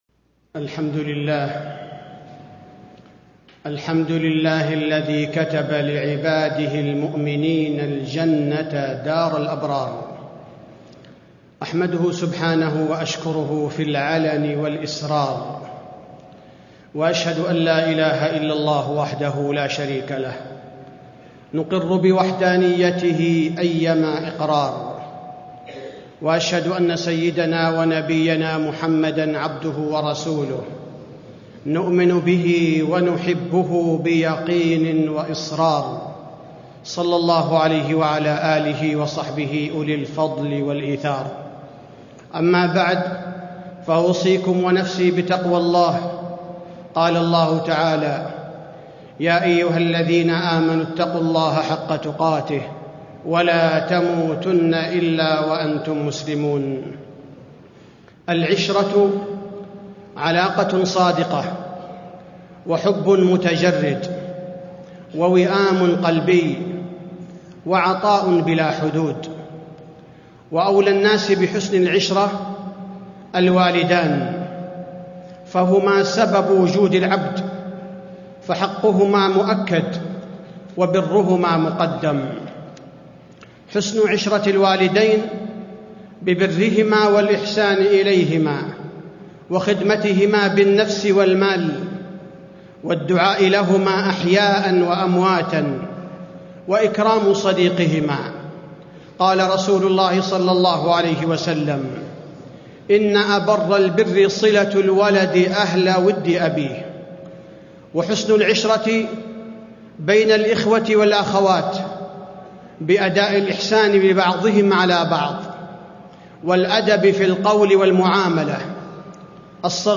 تاريخ النشر ١٢ شوال ١٤٣٥ هـ المكان: المسجد النبوي الشيخ: فضيلة الشيخ عبدالباري الثبيتي فضيلة الشيخ عبدالباري الثبيتي صور من حسن العشرة